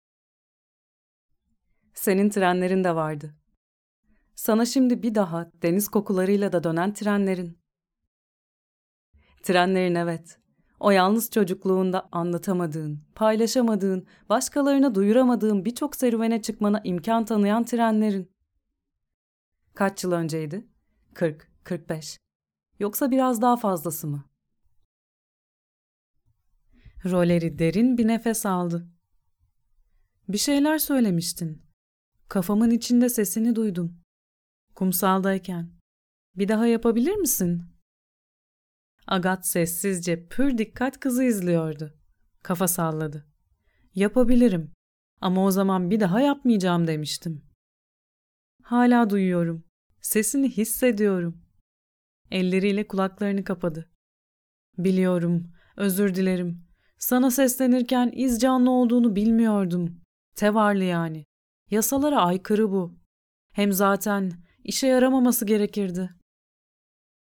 Warm, deep, friendly, natural, feminine, peaceful, Turkish
Sprechprobe: Sonstiges (Muttersprache):